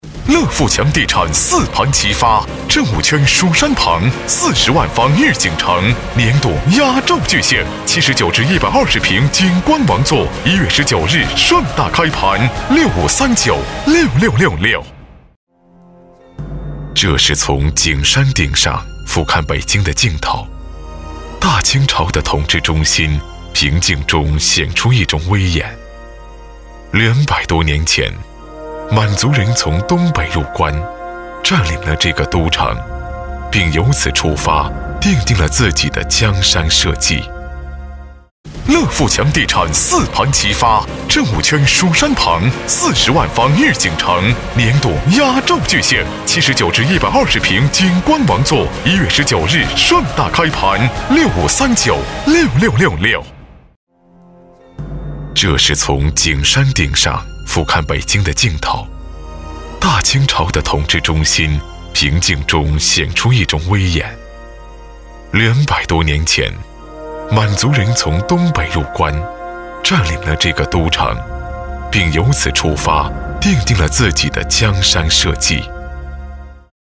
国语青年大气浑厚磁性 、沉稳 、科技感 、男专题片 、宣传片 、200元/分钟男S355 国语 男声 宣传片-大宏立-企业宣传-大气浑厚 大气浑厚磁性|沉稳|科技感 - 样音试听_配音价格_找配音 - voice666配音网
国语青年大气浑厚磁性 、沉稳 、科技感 、男专题片 、宣传片 、200元/分钟男S355 国语 男声 宣传片-大宏立-企业宣传-大气浑厚 大气浑厚磁性|沉稳|科技感